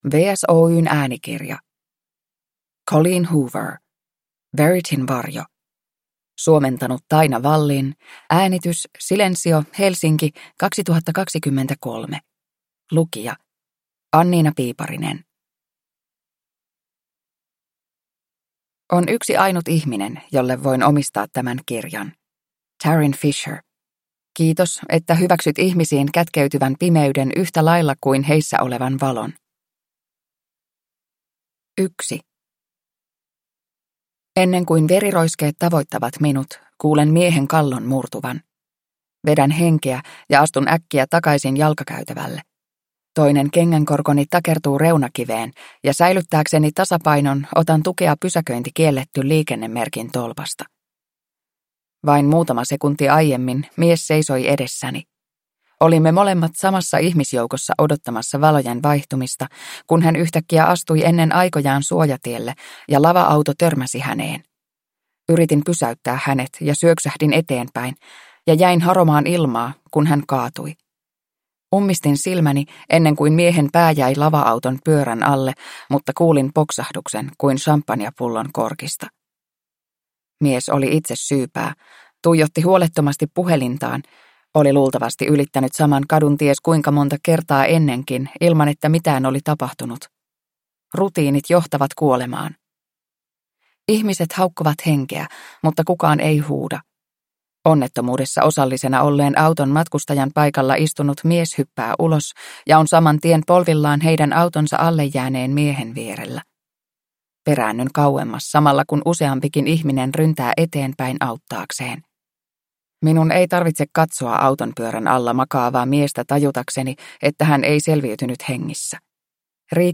Verityn varjo – Ljudbok – Laddas ner